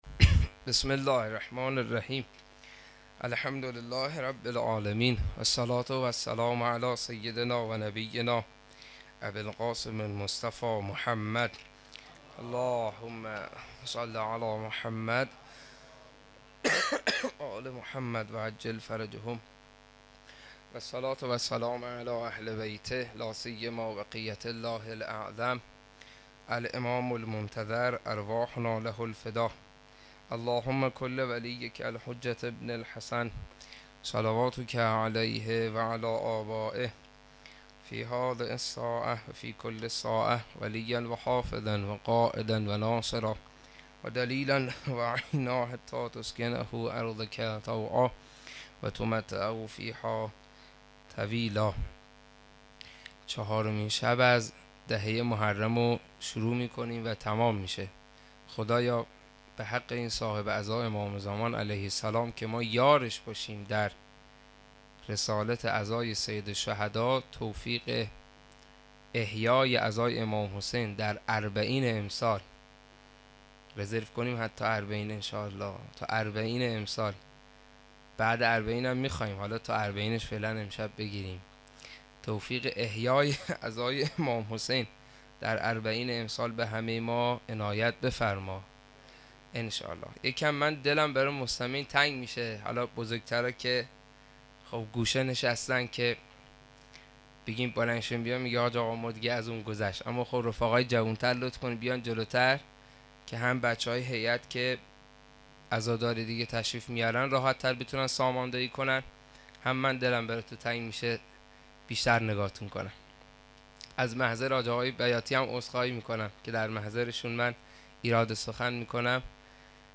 صوت شب چهارم محرم 92 هیئت فاطمیون
صوت شب چهارم محرم 92 هیئت فاطمیون جهت دانلود، میتوان از نرم افزار مدیریت دانلود استفاده کرد؛ در غیر این صورت بر روی نام فایل، راست کلیک کرده و گزینه save target as یا save link as را انتخاب نمایید سخنرانی روضه زمینه واحد تک شور سرود پایانی